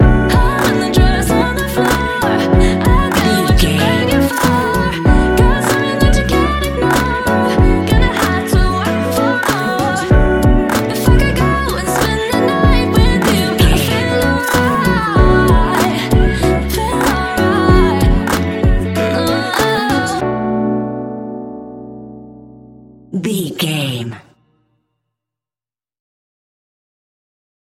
Ionian/Major
B♭
laid back
Lounge
sparse
new age
chilled electronica
ambient
atmospheric